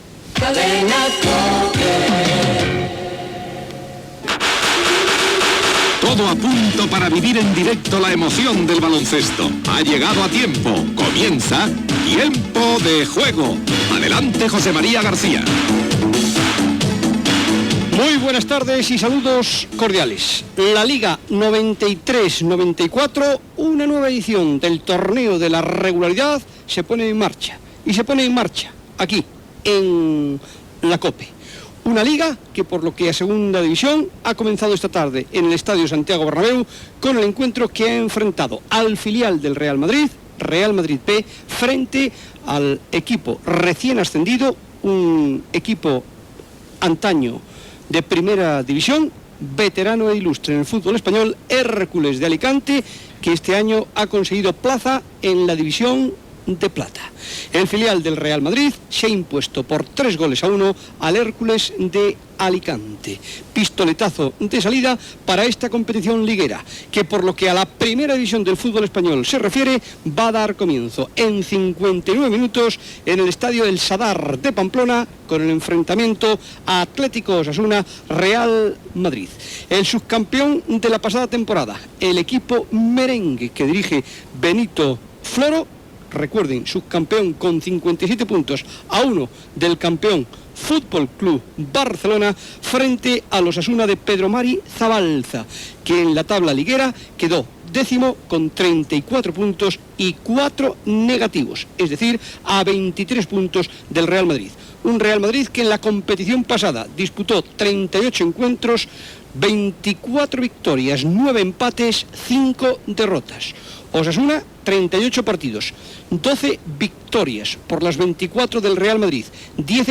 Indicatiu de la cadena, inici de la lliga masculina de futbol de segona divisió, primer partit de primera divisió Osasuna Real Madrid, publicitat, connexió amb el camp del Sadar (Pamplona)
Esportiu